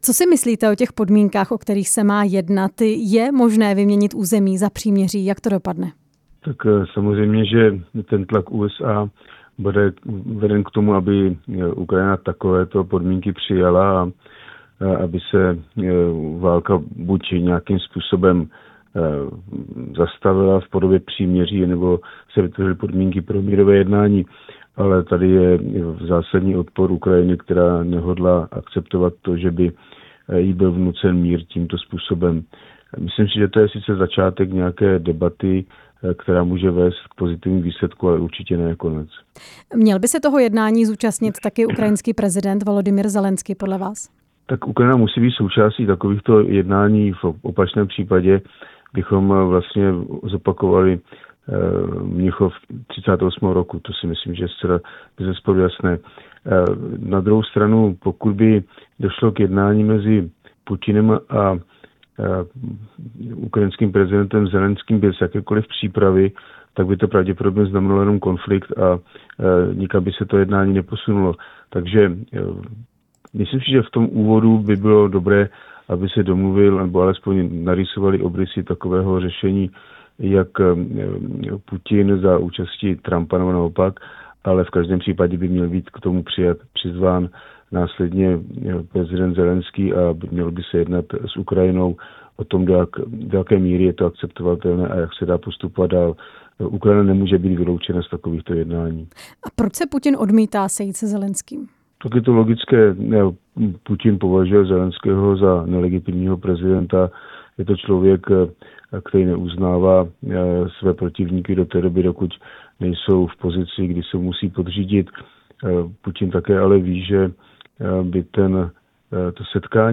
Hostem vysílání Radia Prostor byl Jiří Šedivý, bývalý náčelník generálního štábu.
Rozhovor s bývalým náčelníkem generálního štábu Jiřím Šedivým